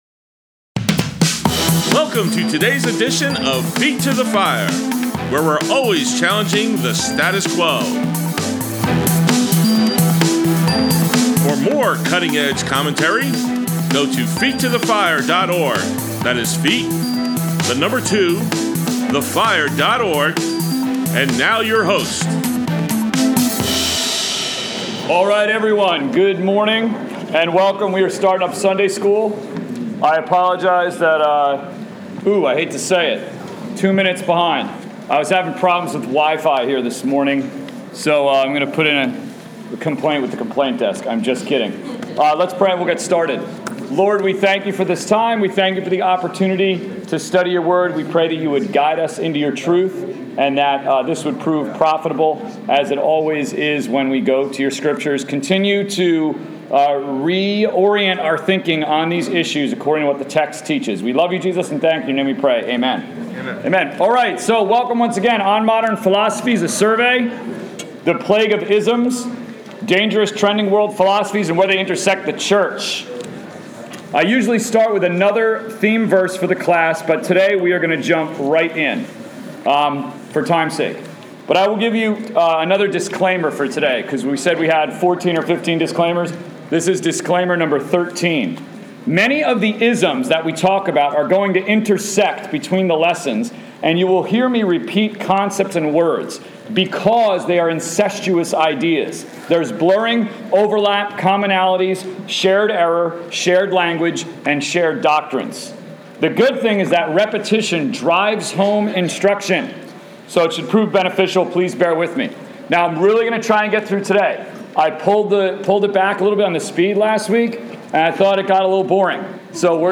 Adult Sunday School